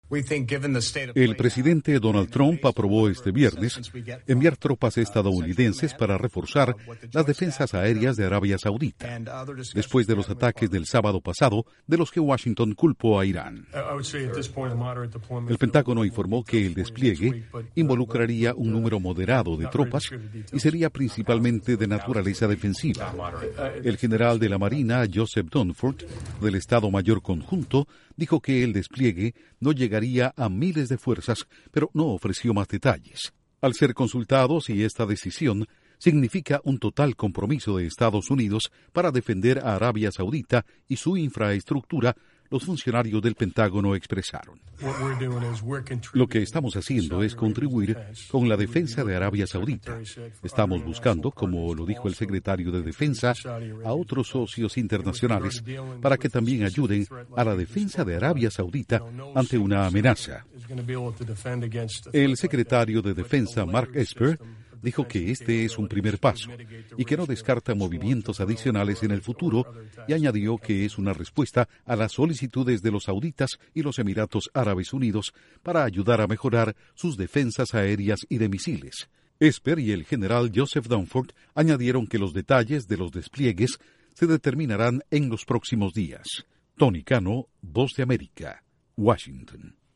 Duración: 1:29 Con audios del Pentágono